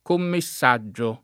Commessaggio [ komme SS#JJ o ]